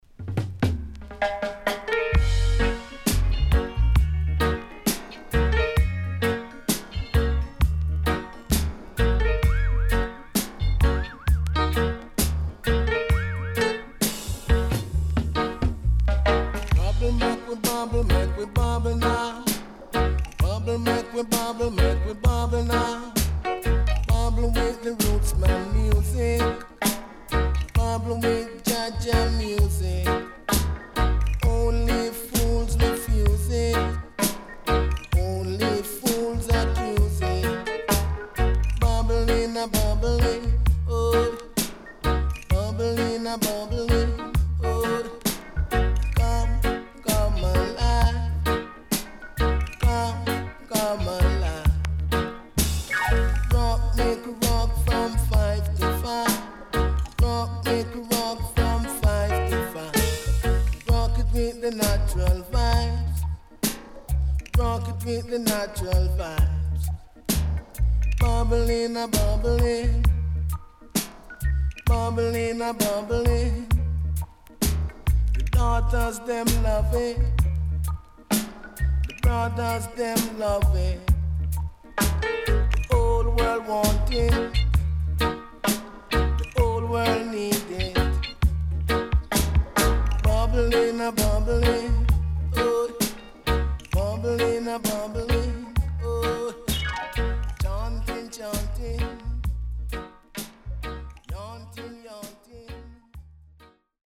Rare.Killer.Nice Vocal
SIDE A:少しチリノイズ、プチノイズ入ります。